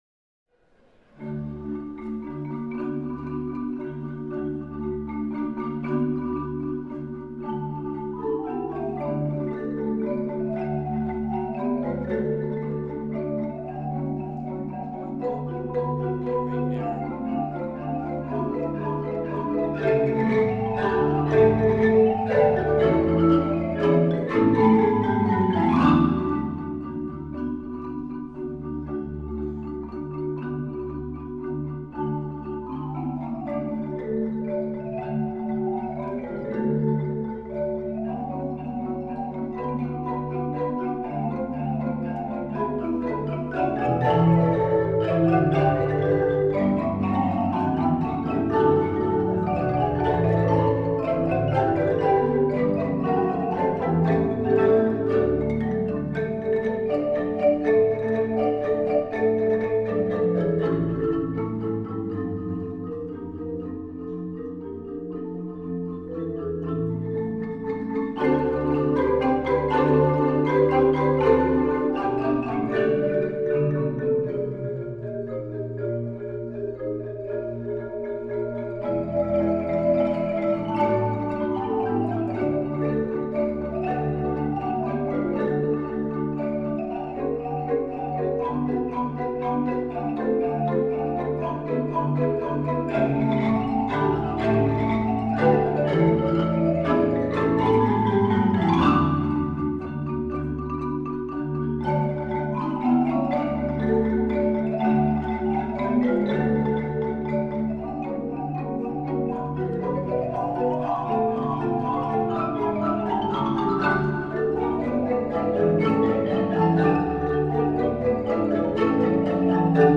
Genre: Percussion Ensemble